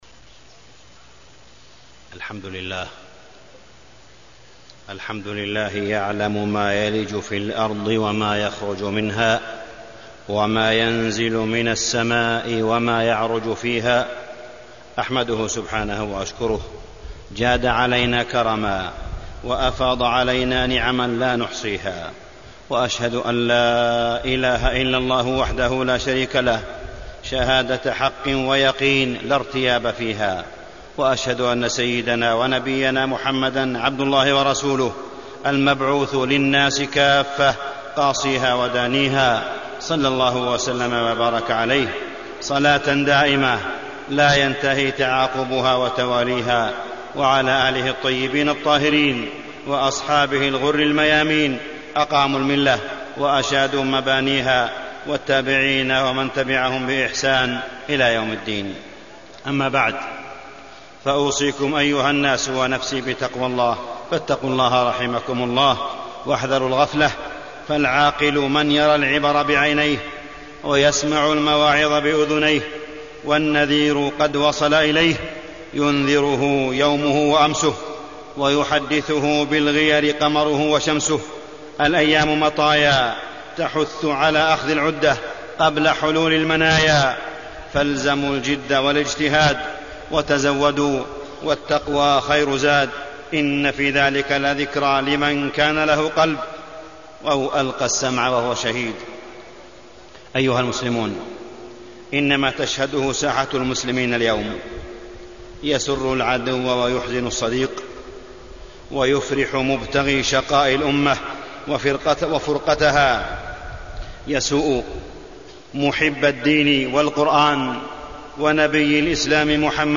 تاريخ النشر ٢١ محرم ١٤٢٨ هـ المكان: المسجد الحرام الشيخ: معالي الشيخ أ.د. صالح بن عبدالله بن حميد معالي الشيخ أ.د. صالح بن عبدالله بن حميد وألف بين قلوبكم